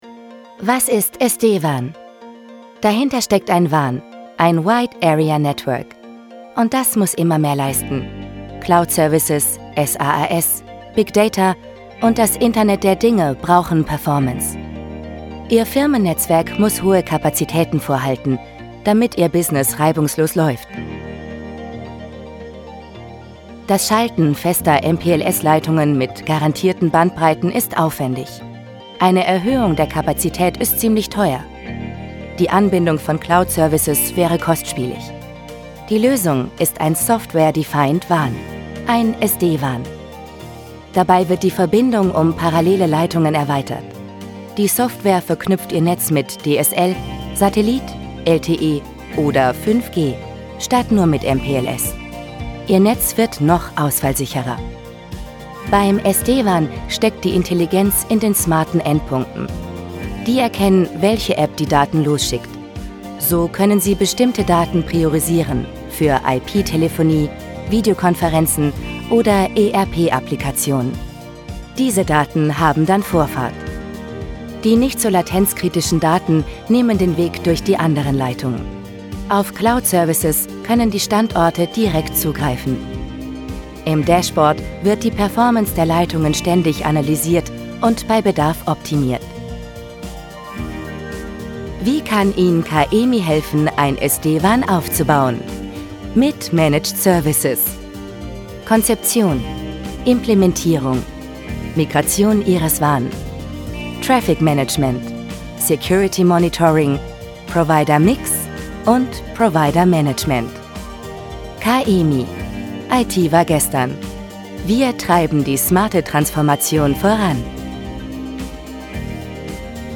Sprechprobe: eLearning (Muttersprache):
Kaemi_Imagefilm_1.mp3